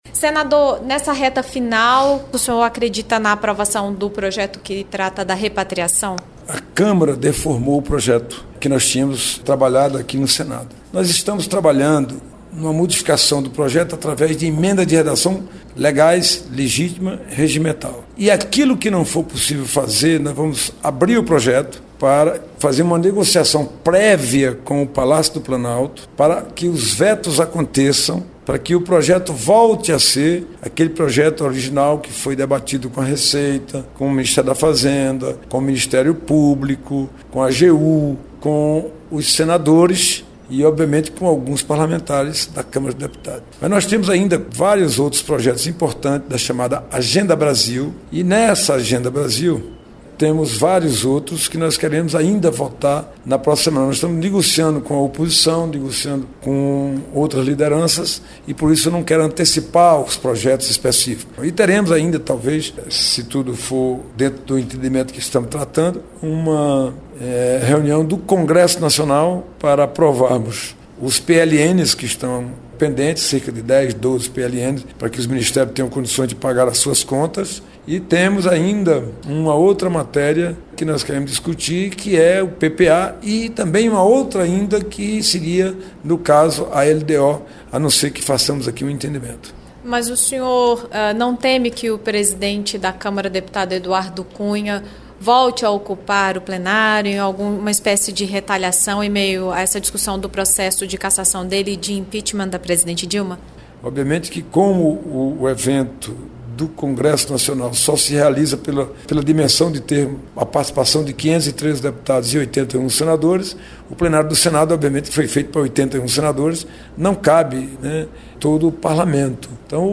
Entrevista com o senador Alvaro Dias